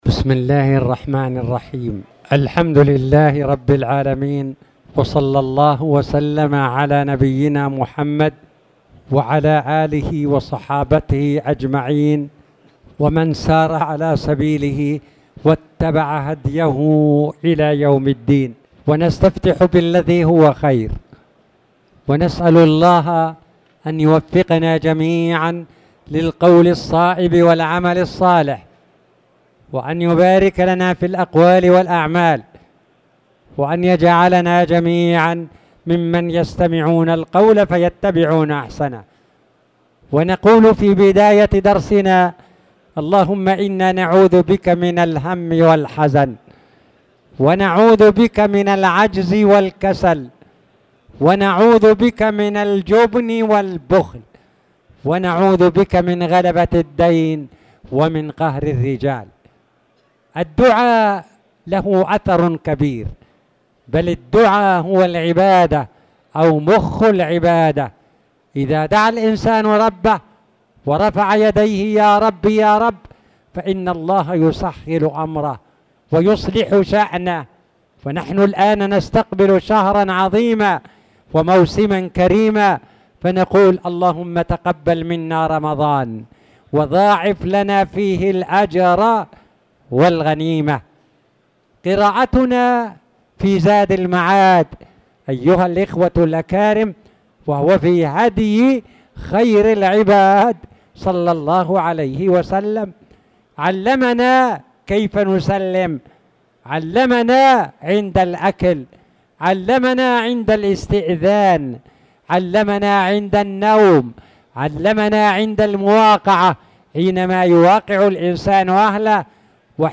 تاريخ النشر ١٥ شعبان ١٤٣٨ هـ المكان: المسجد الحرام الشيخ